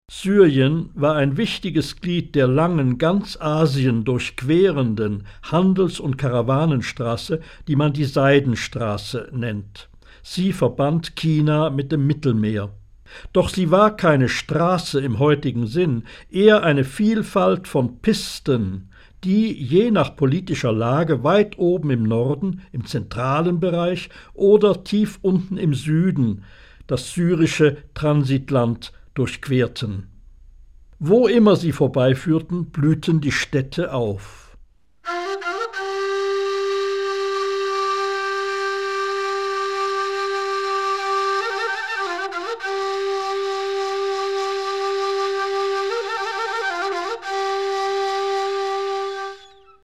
Hörbuch Syrien